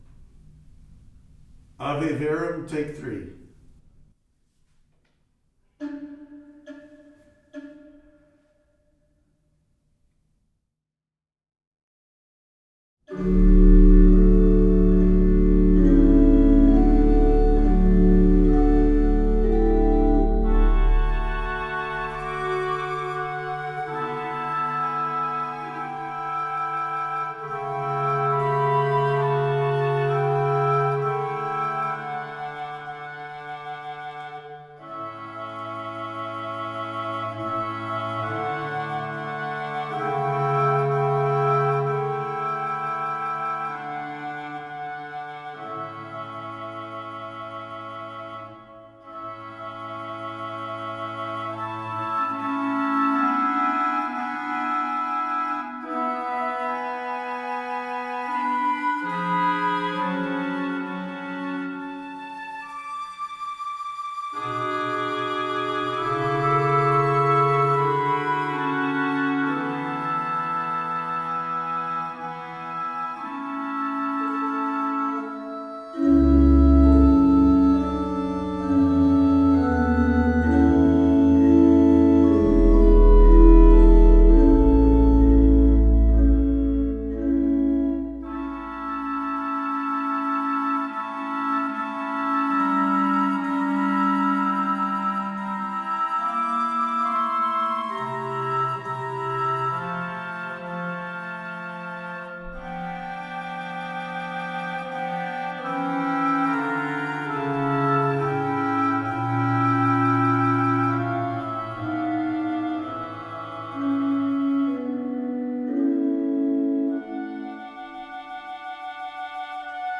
Ave verum corpus (K. 618) is a motet based on the Latin hymn, composed in 1791 by Wolfgang Amadeus Mozart to celebrate the feast of Corpus Christi.
Mozart’s original motet was scored for choir, string instruments, and organ. This arrangement for orchestral wind quartet and organ is played as an organ solo, featuring a new technology called “Enhanced Orchestral Couplers” in which the organist can effortlessly play multiple distinct instruments on the same manual.